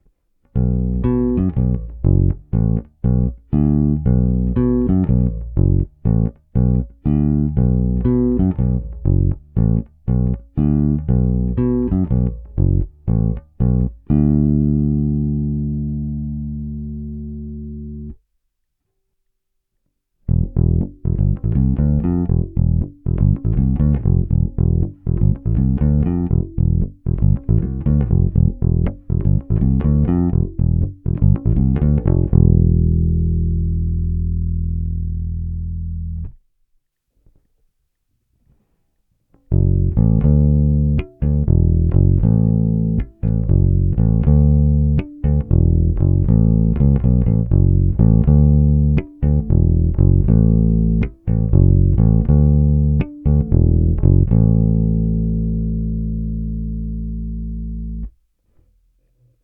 Následující nahrávky jsou nahrávány přímo do zvukovky, bez jakýchkoli úprav (mimo normalizace).
Nahráno na skládačku precision, struny La Bella Deep Talkin Bass flatwounds, tónová clona otevřená.
Bez preampu